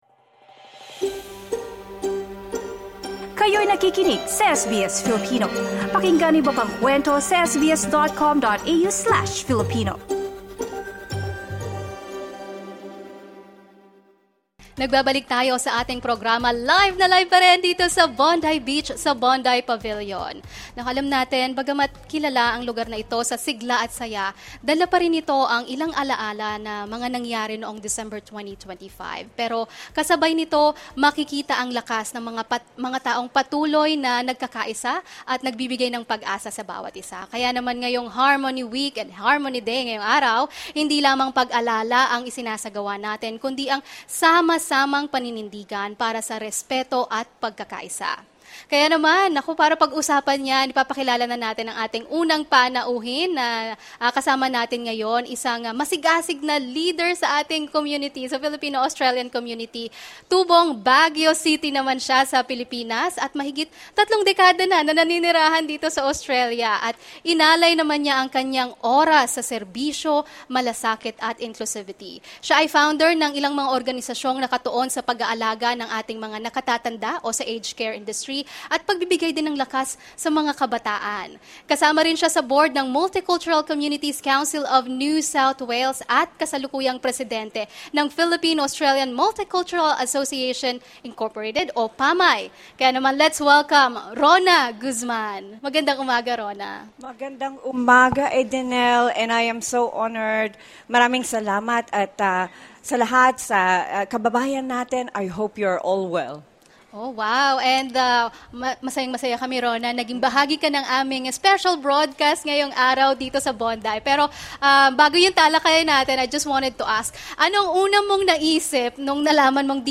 During the SBS Filipino live broadcast at Bondi